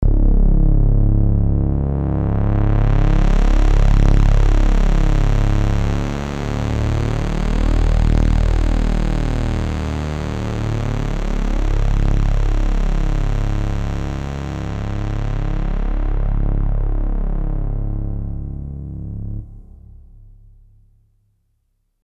Yamaha CBXK1 + Tascam RS-30 = Cheap Digital Heaviness
Filter sweep and pitch bend
And this filter sweep is amazing, makes me think of the rings of Saturn and the sun off in the distance!!